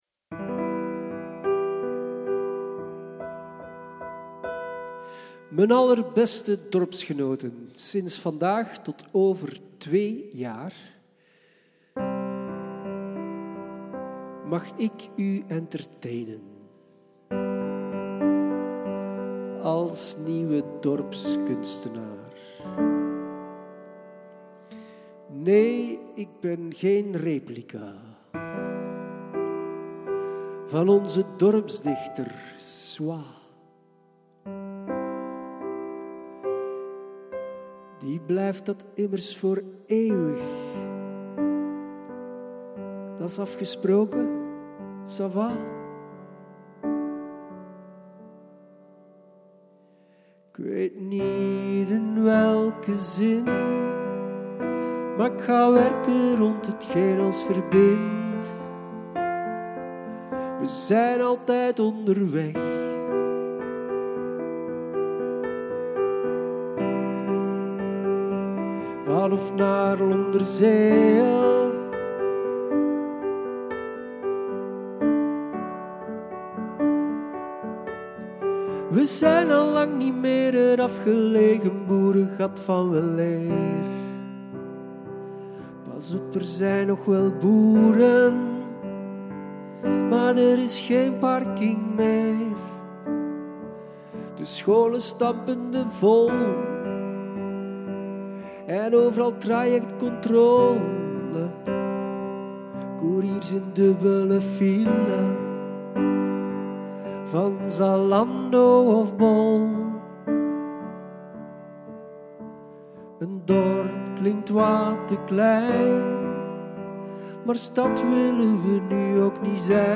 Tijdens de Nieuwjaarsdrink bracht hij in primeur het nummer